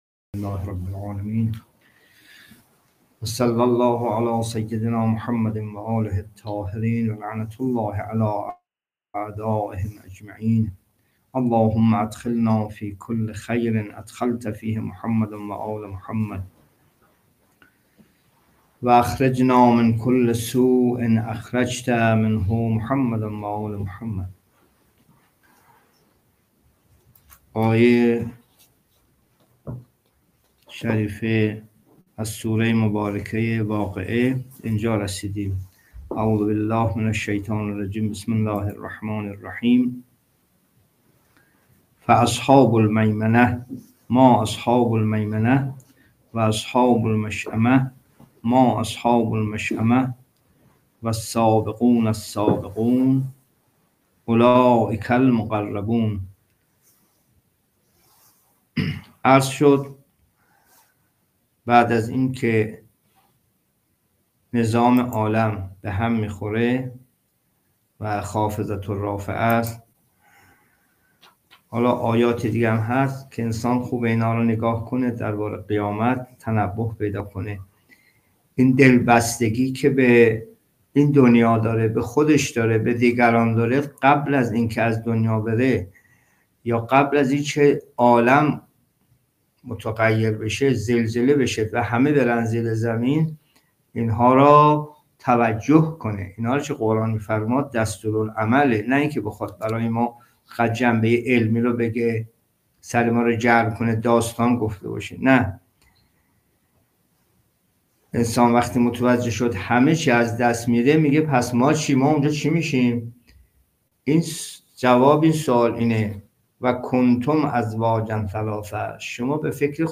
جلسه تفسیر قرآن (31) سوره واقعه